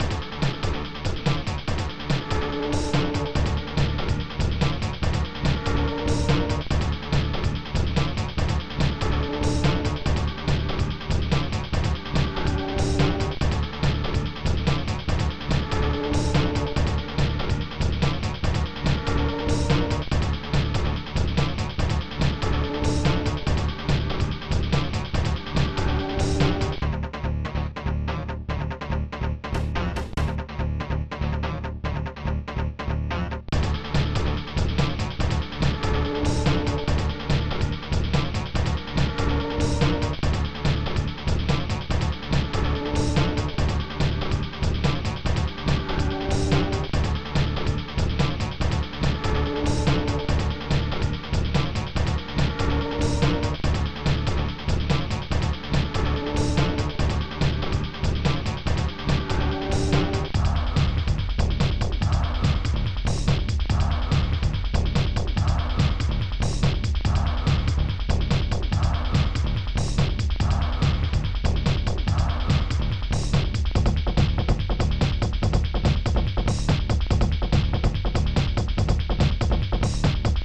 mod (ProTracker MOD (6CHN))